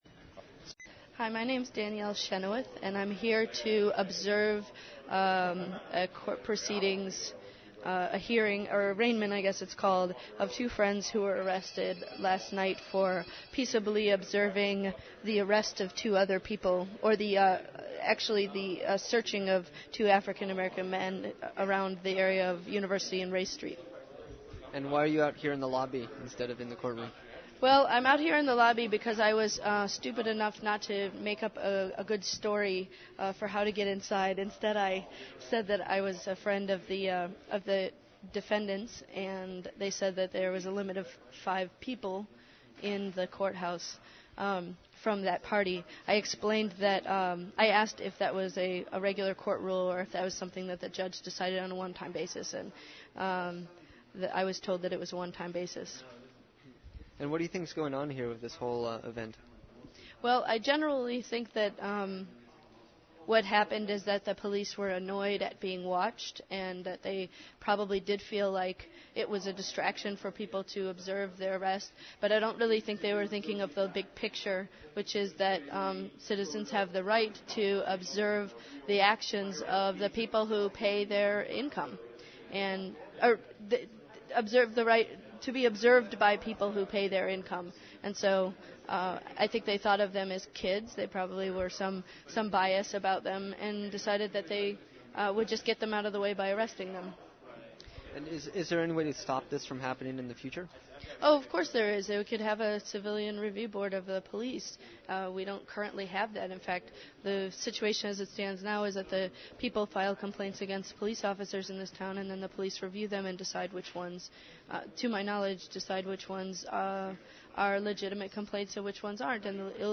Councilperson Danielle Chynoweth describes the arrest of two witnesses and proposes a civilian review board to monitor complaints against police. (5:53)